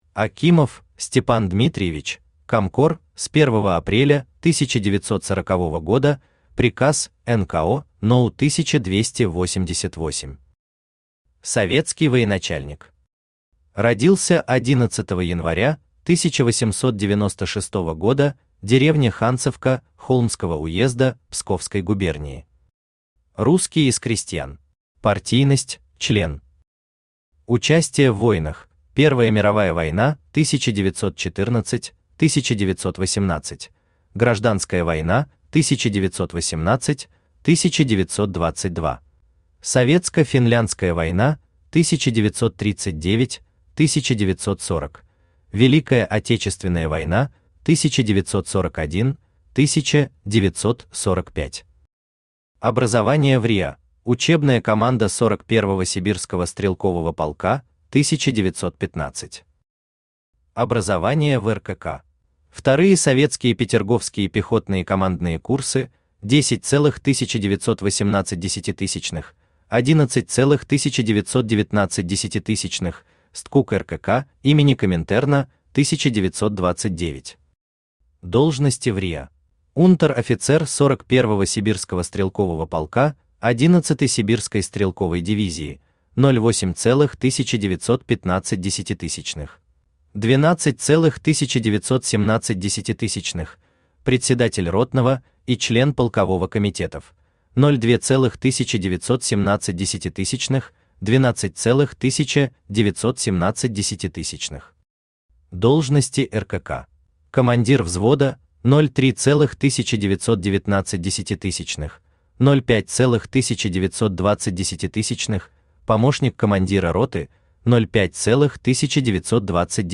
Аудиокнига Высший командный состав РККА. Комкоры 1935-1940 гг.
Автор Денис Соловьев Читает аудиокнигу Авточтец ЛитРес.